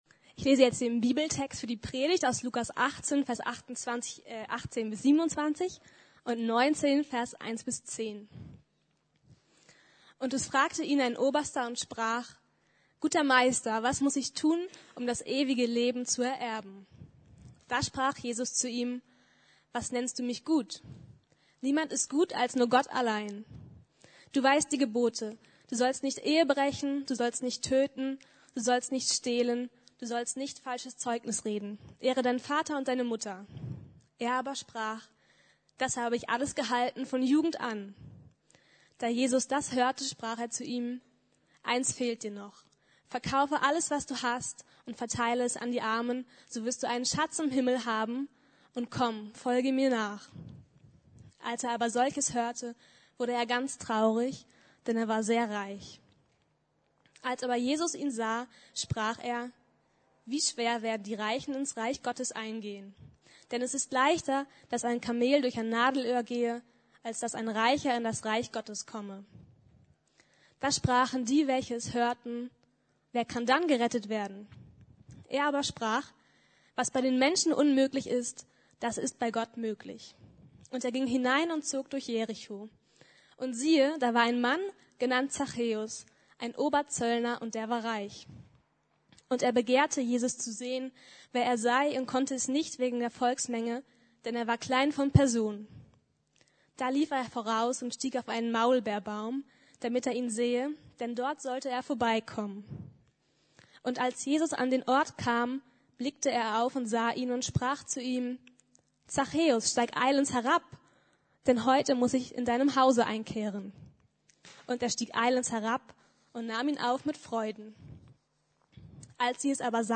Jesus begegnen! ~ Predigten der LUKAS GEMEINDE Podcast